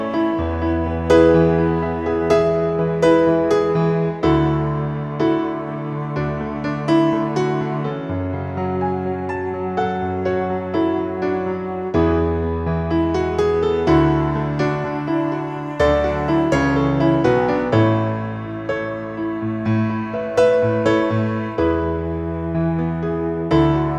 Original Male Key